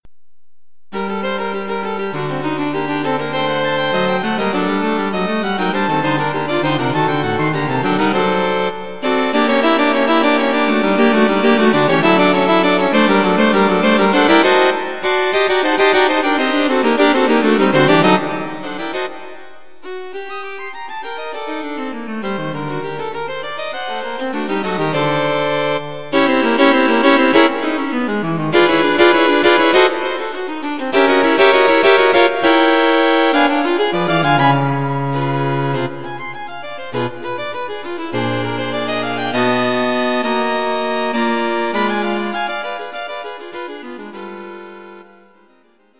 String Quartet for Concert performance
A modern, fast piece for String Quartet.